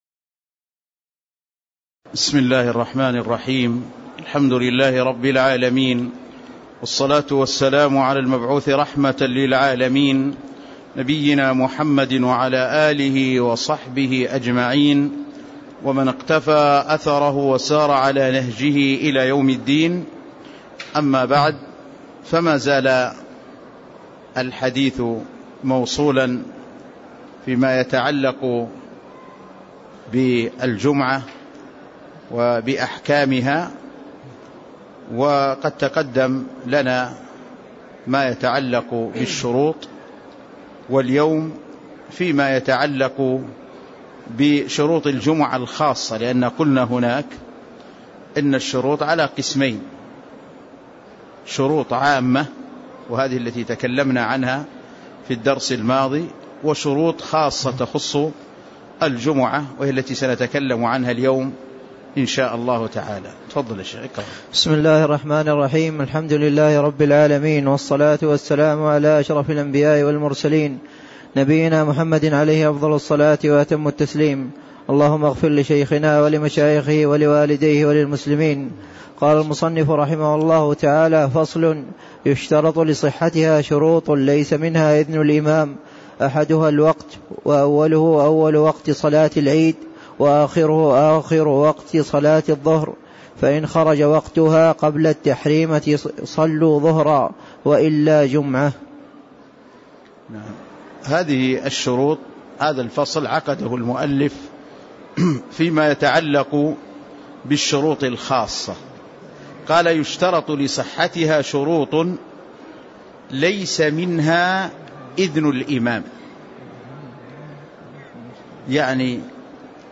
تاريخ النشر ١ ربيع الأول ١٤٣٦ هـ المكان: المسجد النبوي الشيخ